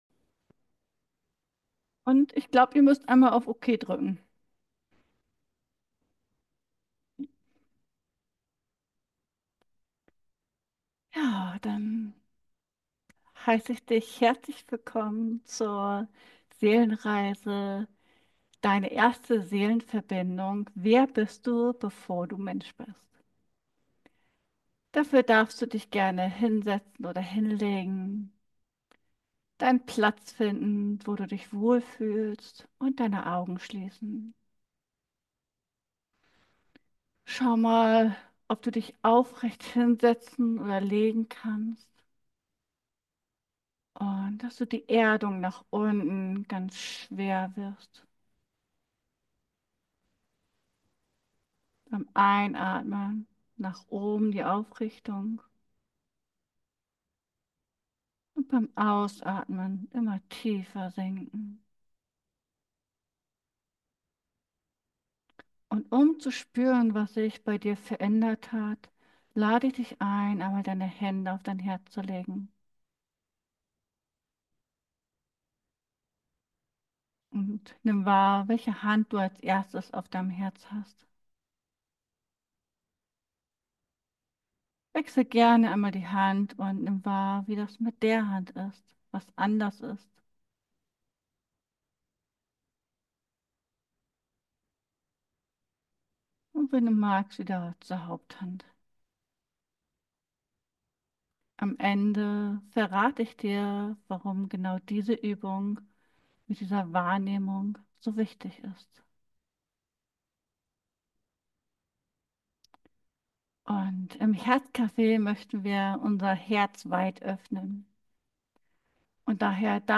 In dieser geführten Reise lade ich dich ein, dich auf eine transformative Entdeckung zu begeben. Finde deinen sicheren Platz, atme tief ein und lass dich von der Erdung nach unten und der Anbindung nach oben leiten.